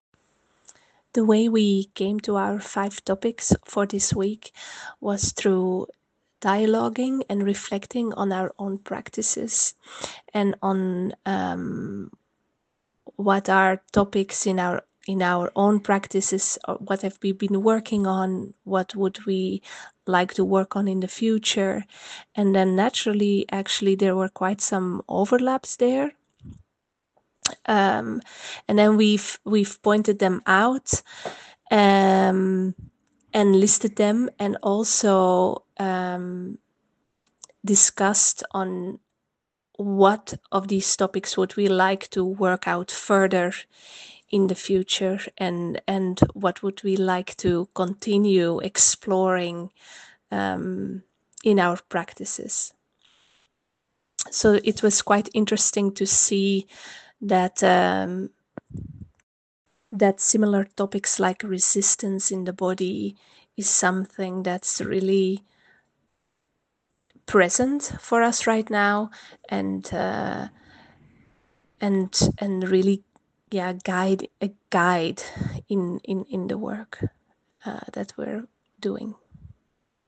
Title Netherlands - collective audio contribution ContentConcept audio conversation Type Audio Tagen på plats The Netherlands Tier 3.